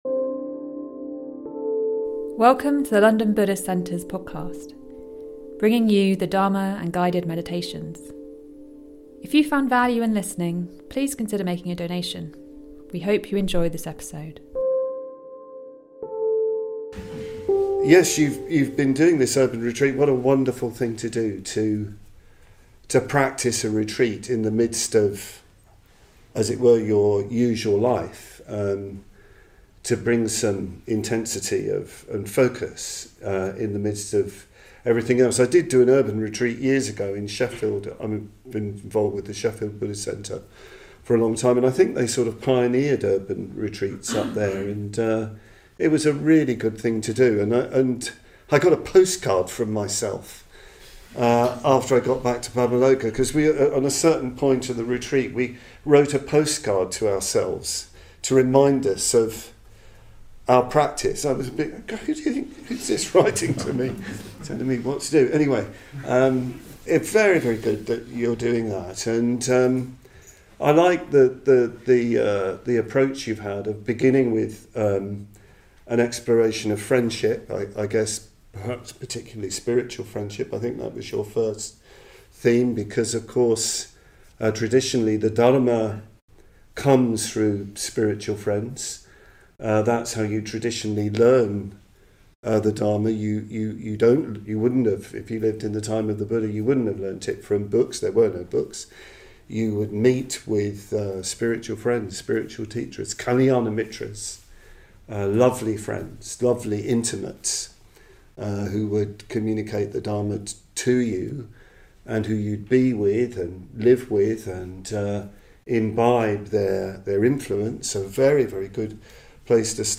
On the Urban Retreat 2023, we explored the theme: What the World Needs Now . Hear responses from experienced Buddhist practitioners.